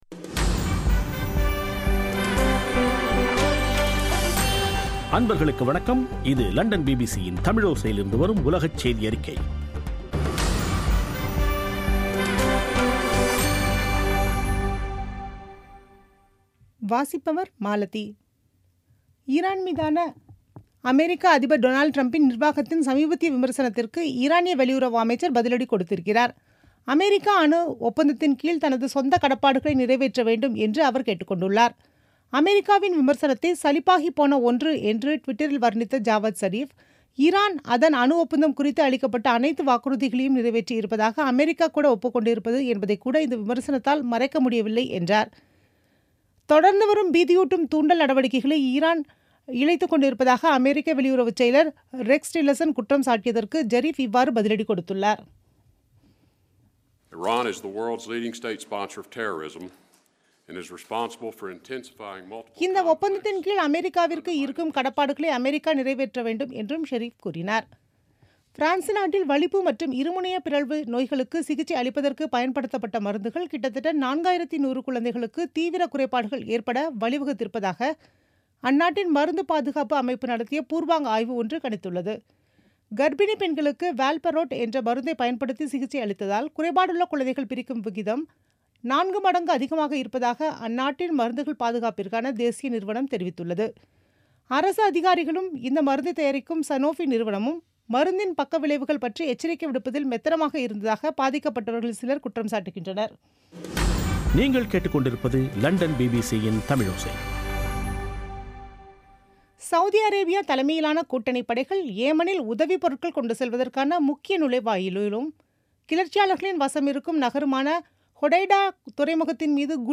பிபிசி தமிழோசைசெய்தியறிக்கை (20/04/2017)